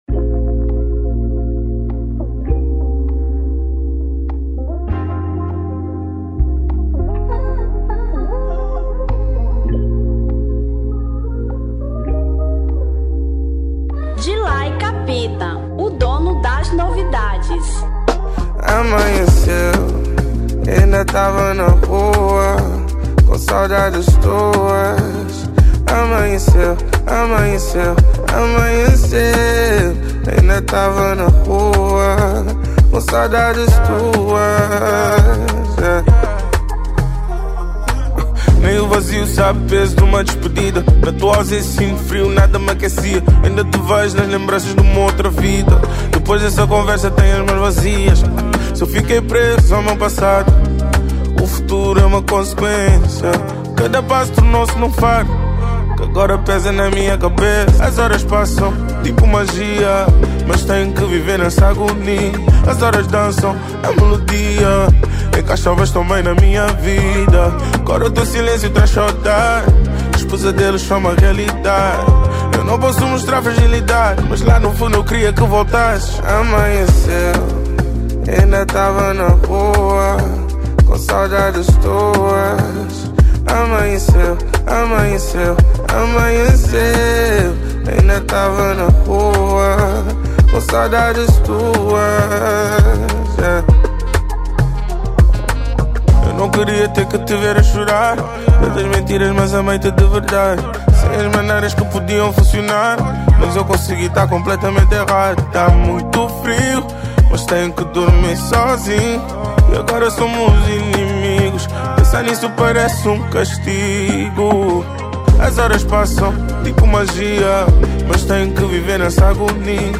Afro Pop 2025